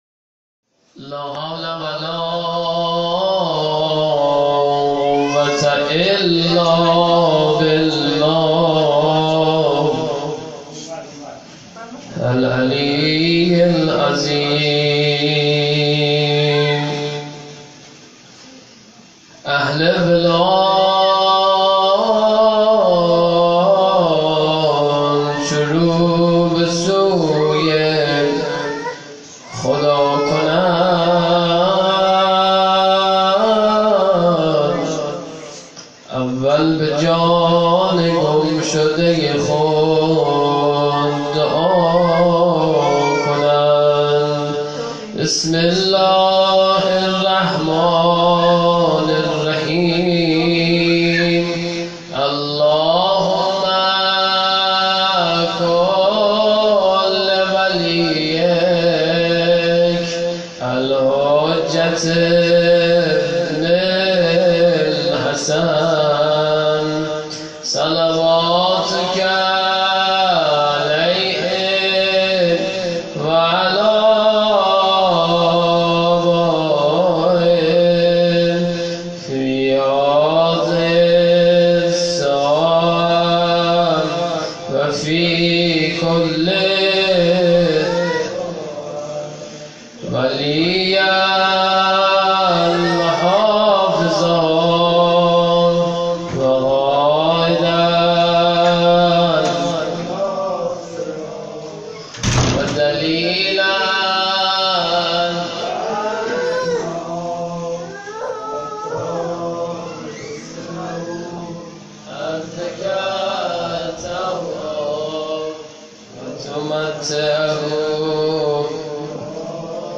هیأت زوارالزهرا سلام اللّه علیها